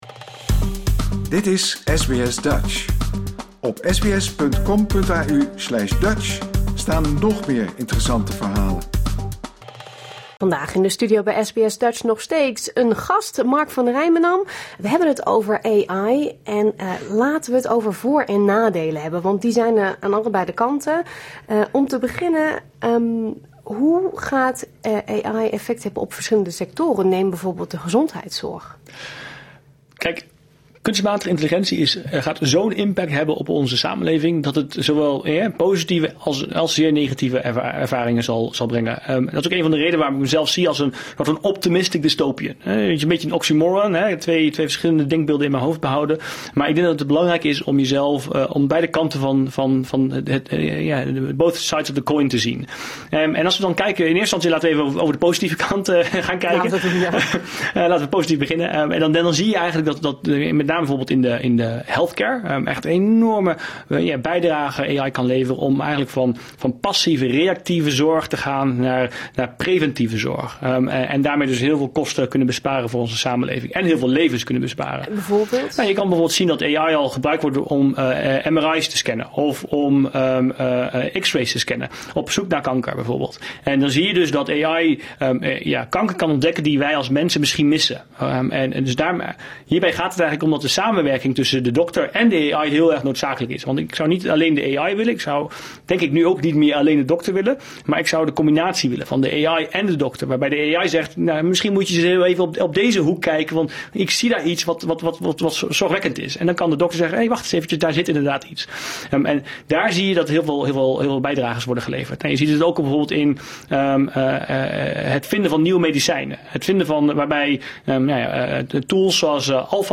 te gast in de studio van SBS Dutch